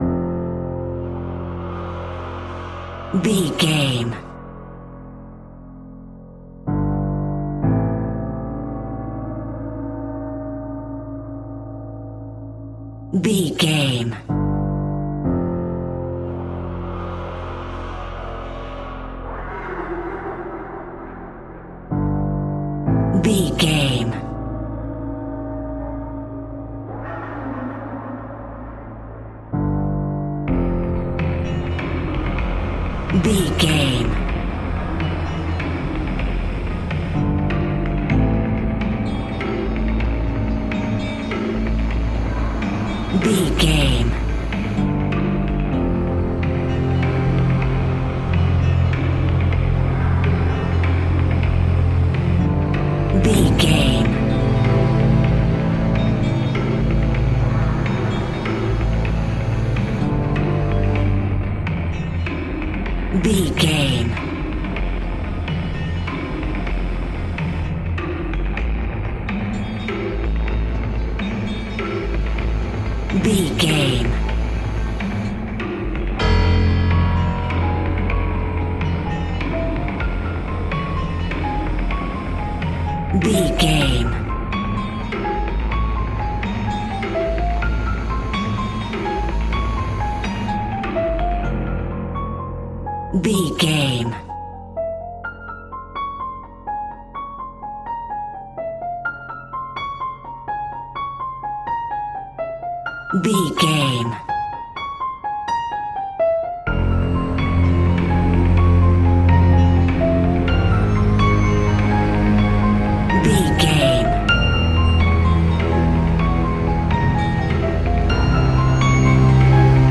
Industrial Scary Music Theme.
In-crescendo
Aeolian/Minor
tension
ominous
eerie
piano
strings
percussion
synth
pads